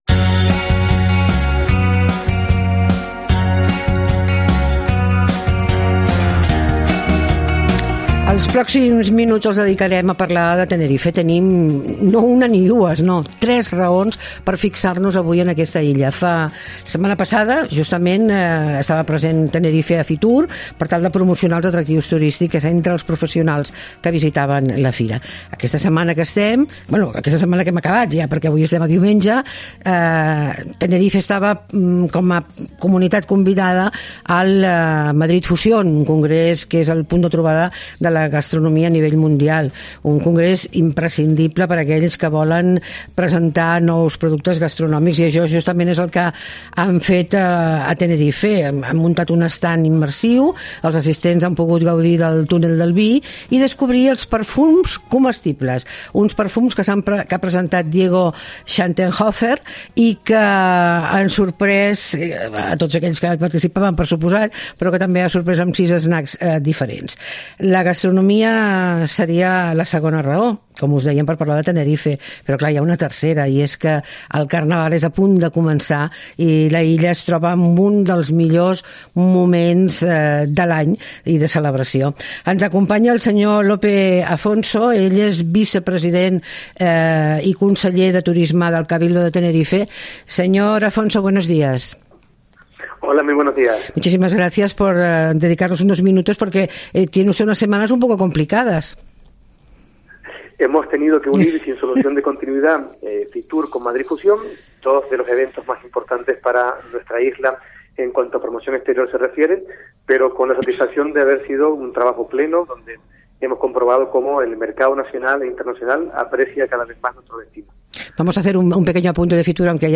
Todos los domingos hacemos una hora de radio pensada para aquellos que les gusta pasarlo bien en su tiempo de ocio ¿donde?